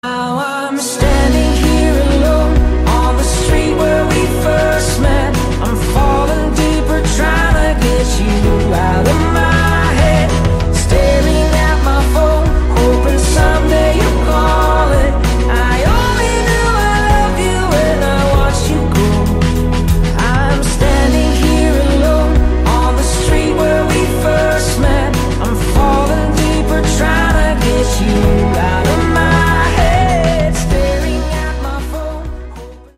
Pop Musik